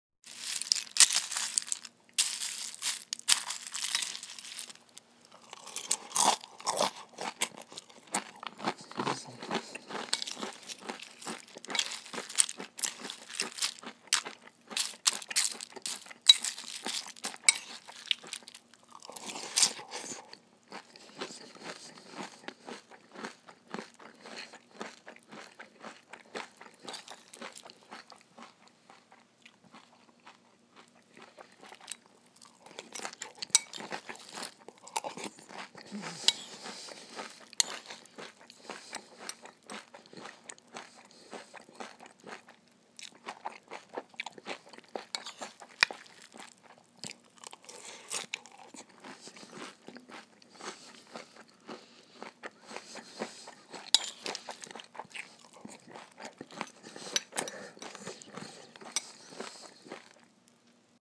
eating-cereal.m4a